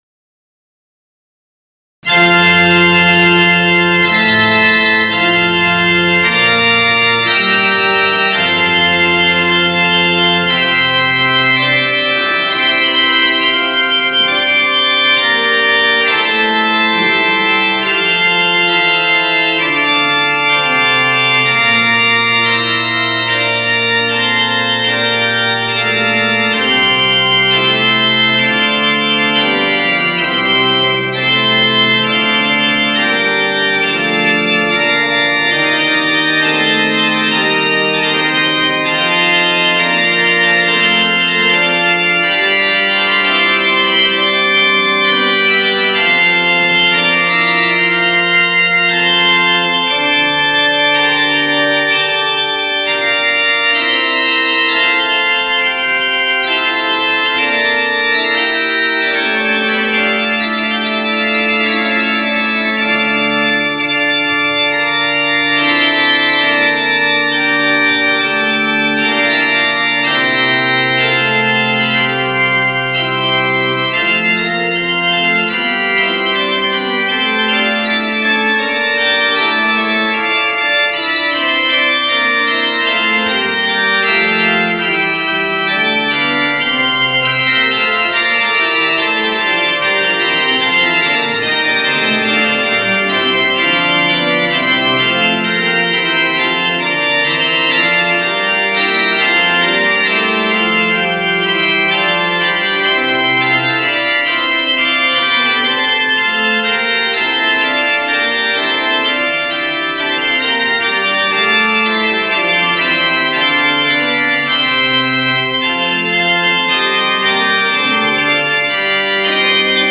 Musiche Organo Damiani
Dal concerto 25/09/1999: Maestro Gustav Leonhardt (Amsterdam † 2012) : J. P. Sweelinck (1562-1621) Praeludium Toccata